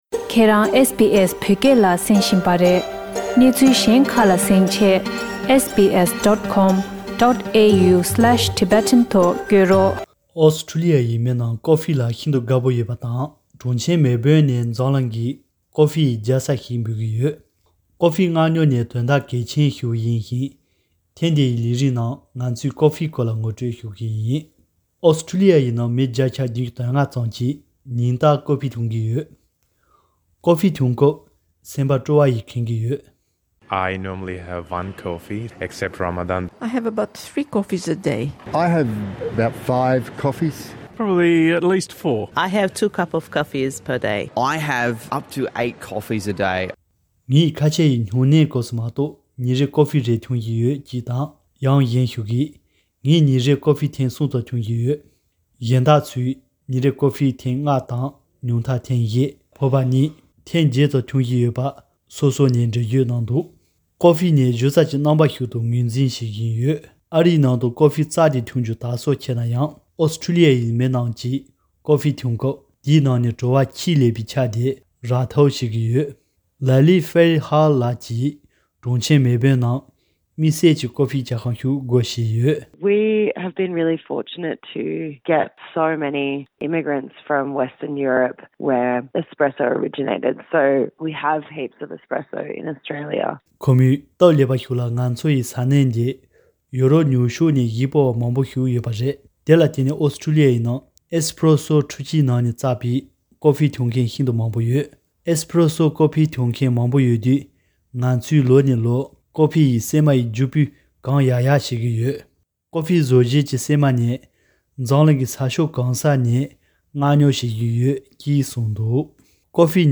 ཀོ་ཕི་འཐུང་ས་མི་ཁག་ཅིག་ལ་བཅར་འདྲི་བྱས་སྐབས།